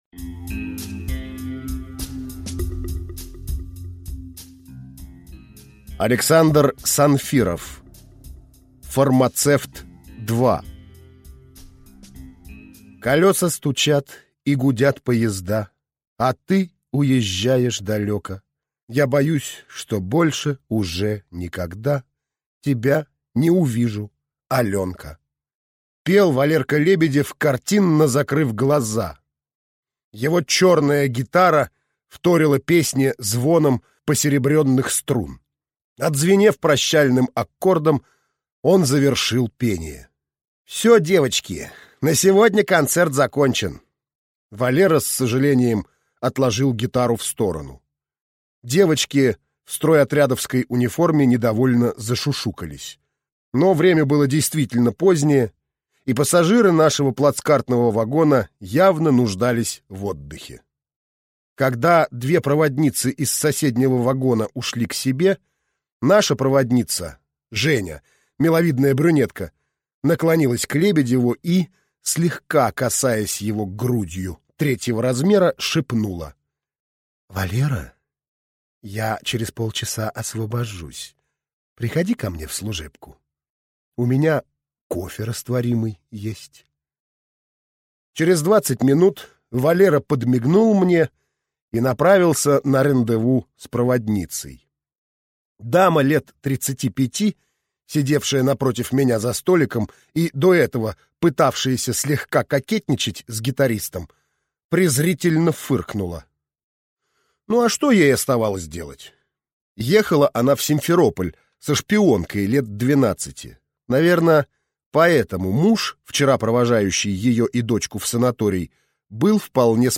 Аудиокнига Фармацевт 2 | Библиотека аудиокниг